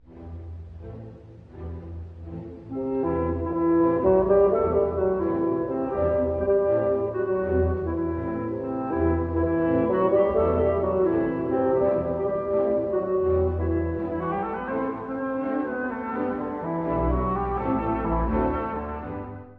(序奏) 古い音源なので聴きづらいかもしれません！
主部は Allegro con anima, 6/8拍子。
弦楽器の、暗い雪道を踏みしめて歩くようなリズム
そこに、Cl,Fgの幽玄な調べが奏でられます。
また、”con anima”から分かるように、暗いながらも動きのある楽章です。